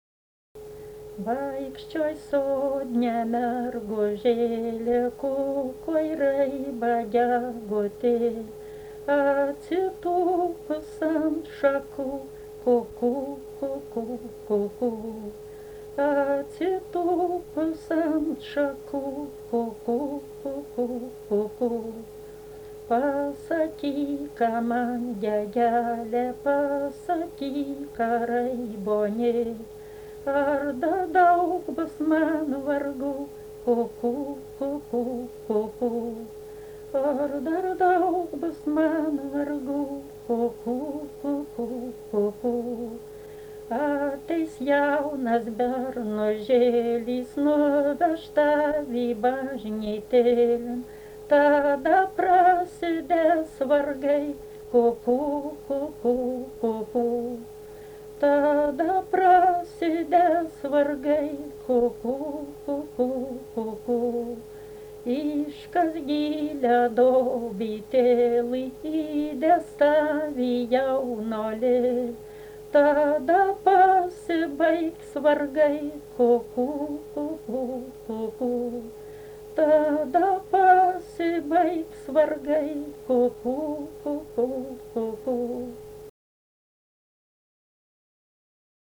daina
Čypėnai
vokalinis